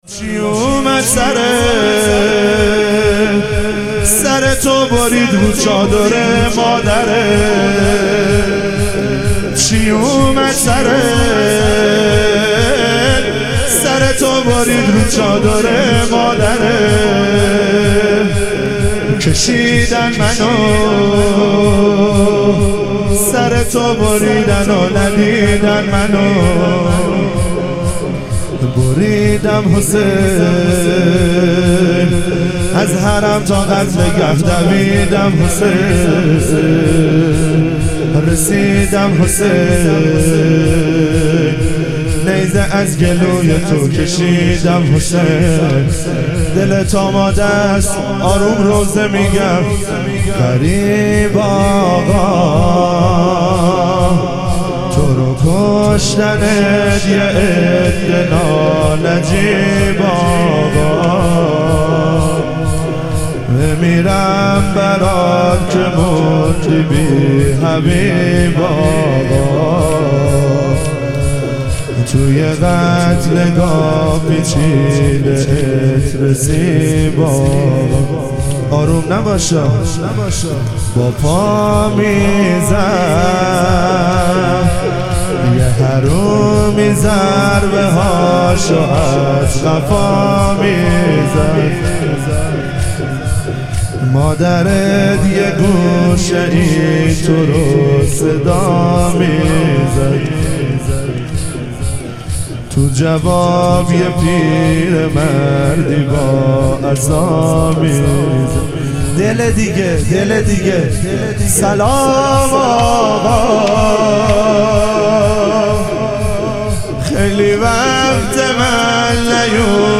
اربعین امام حسین علیه السلام - شور